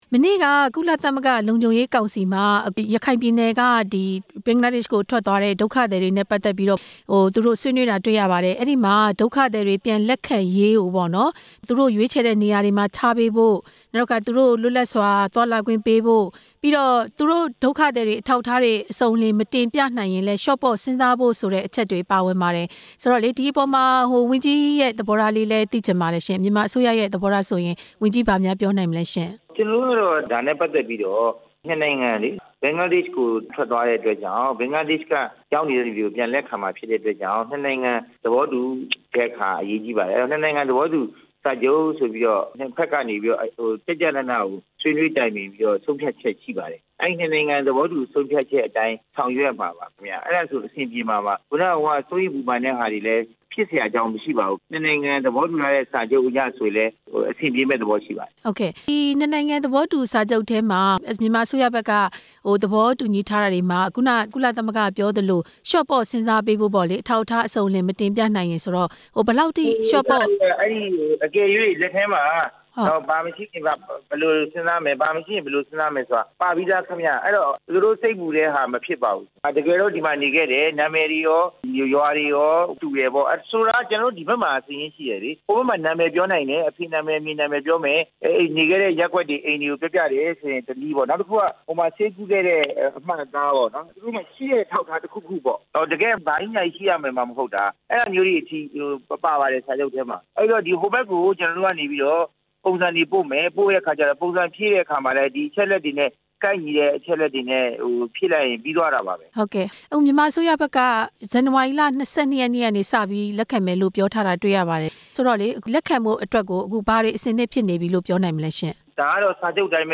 ဒုက္ခသည်တွေ လက်ခံရေးအကြောင်း ဆက်သွယ်မေးမြန်းချက်